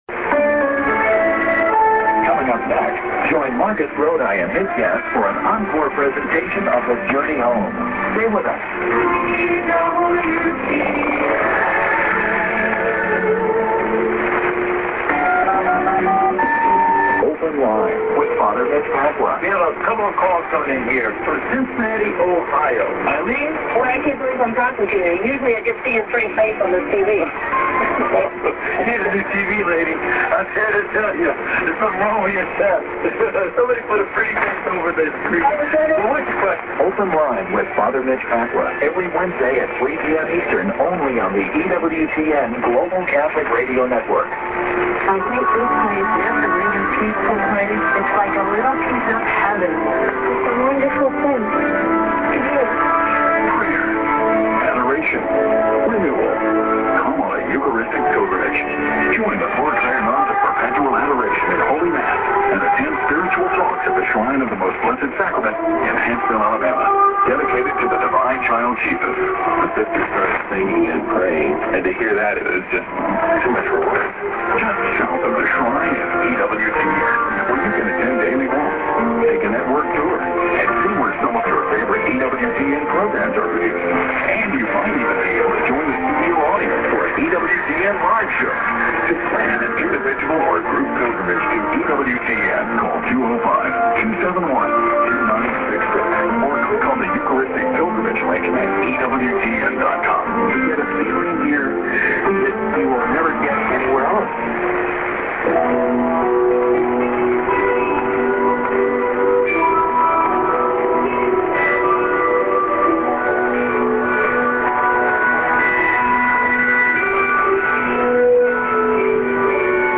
->ANN(man+women:ID:EWTN)->ANN(women:ID+SKJ:WEWN)->prog　00'40":EWTN->02'30":WEWN